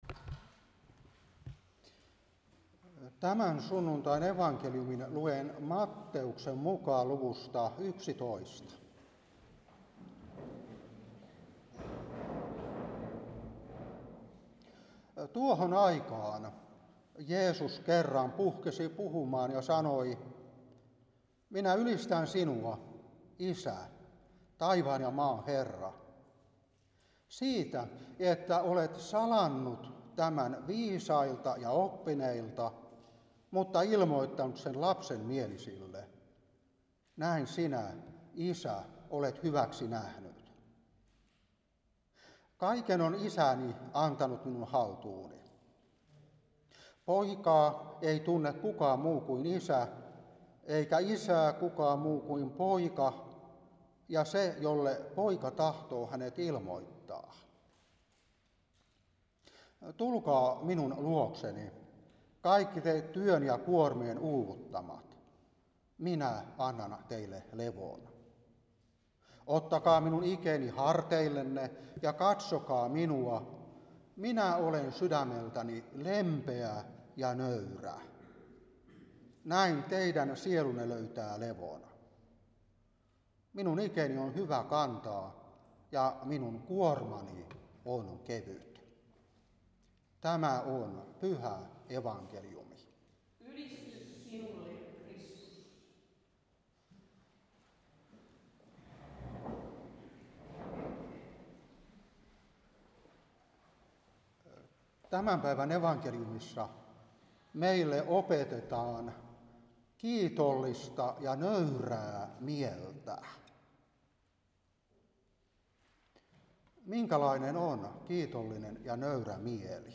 Saarna 2024-9.